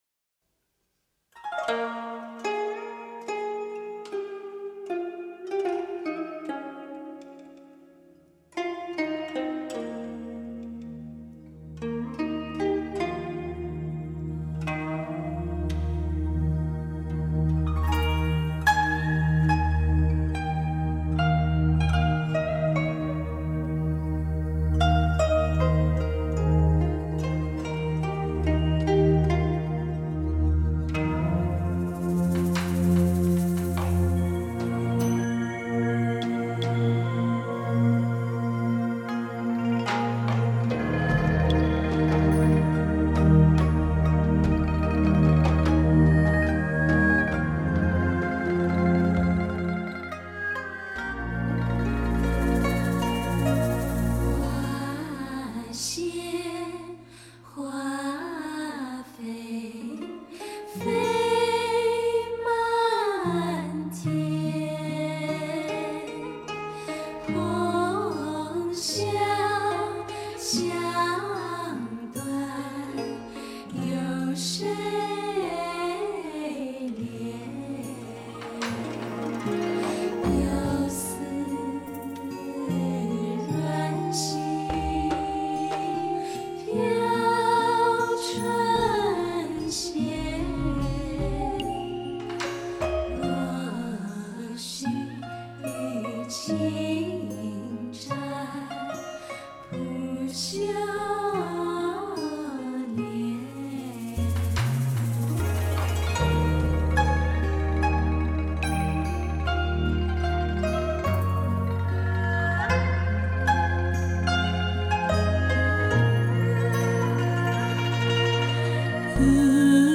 dts 5.1声道音乐
极至女声伴您畅游辽阔草原，
马头琴声邀您共赏民乐瑰宝，天籁音乐HIFI享受，
乐器定位精准极致，声场宏大环绕效果显著，